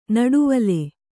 ♪ naḍuvale